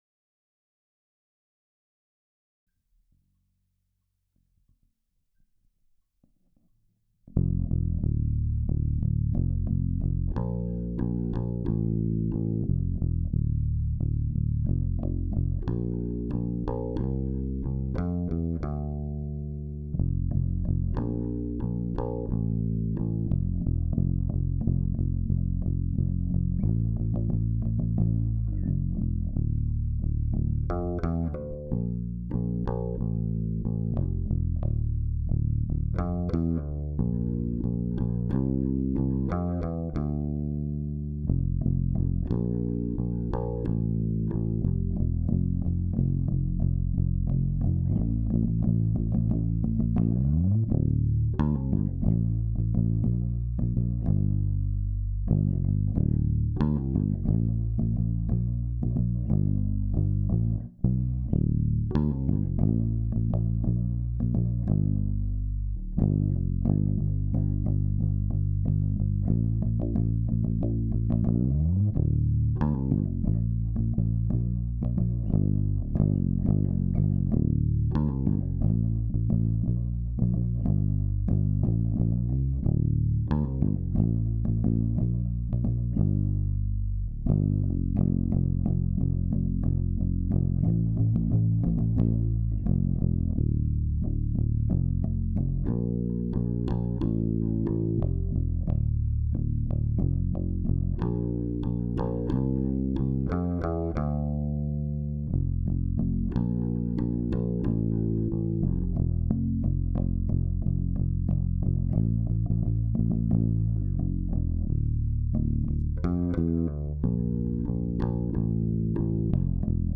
pozytywka_bass.wav